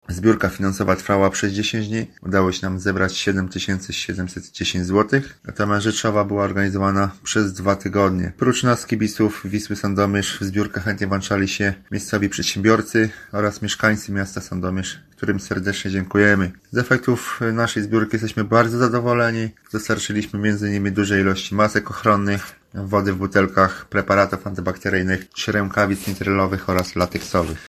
kibic 'Wisły’ Sandomierz